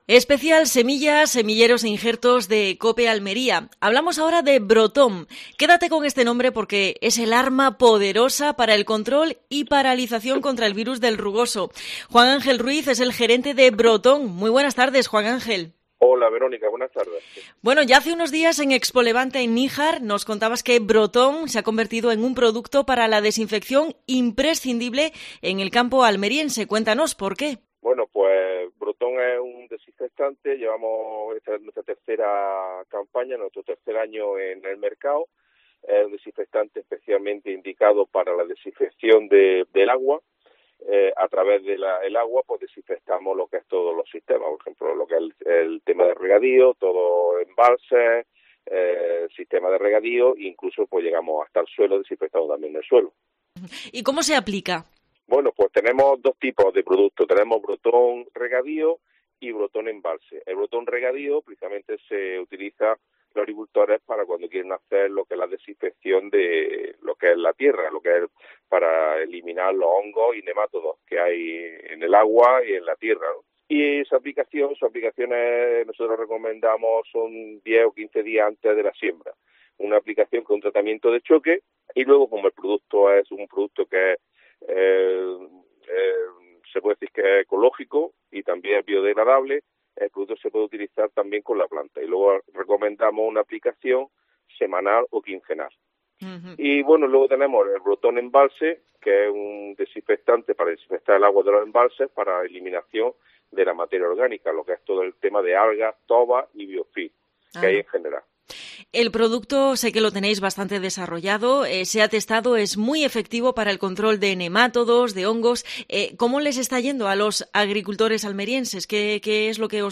AUDIO: Programación especial de COPE Almería desde Tecnobioplant.